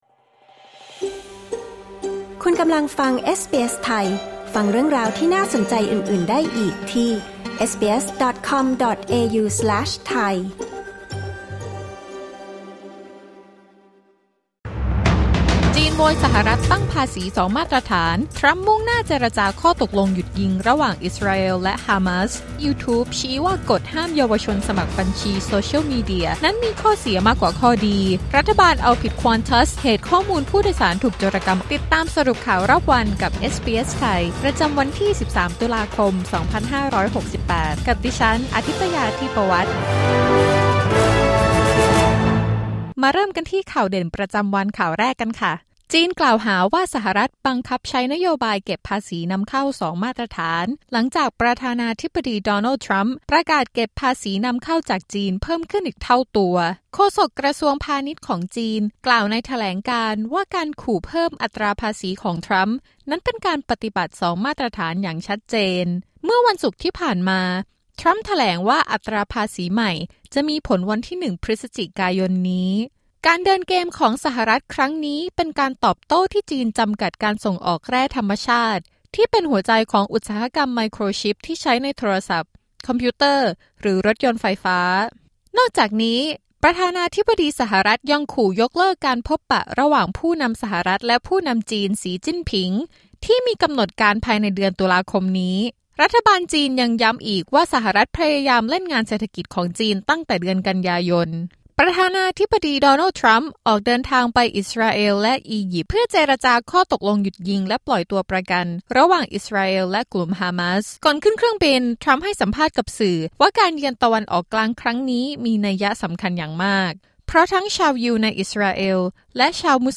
สรุปข่าวรอบวัน 13 ตุลาคม 2568